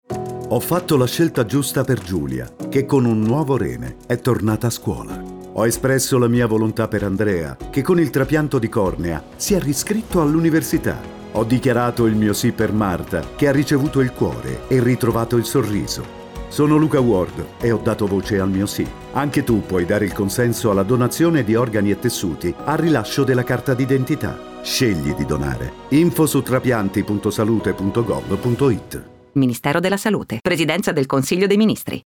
Lo spot radio
Claim della campagna è “Dai voce al tuo sì, scegli di donare” ed è stato scelto quale testimonial Luca Ward, noto attore e doppiatore.